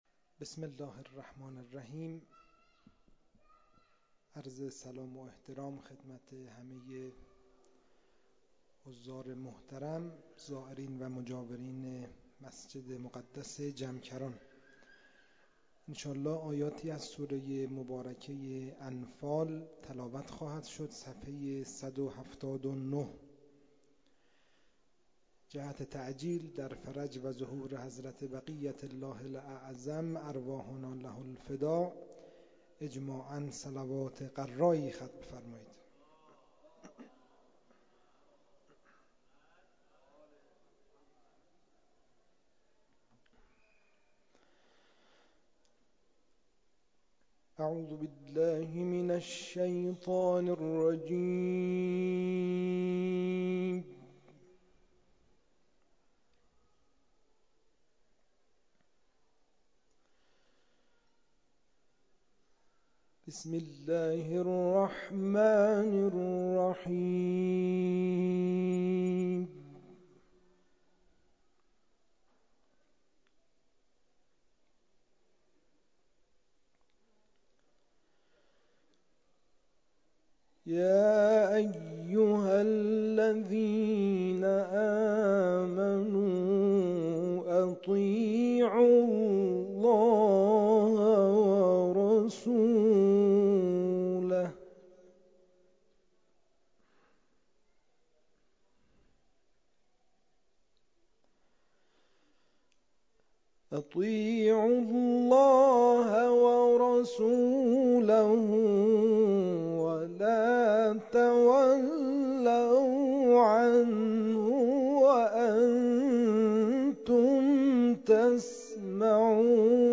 کرسی اذانگاهی تلاوت مهدوی فردا برگزار می‌شود + صوت
کرسی تلاوت مهدوی سه‌شنبه این هفته با تلاوت یکی دیگر از قاریان بین‌المللی کشورمان از استان قم همراه است.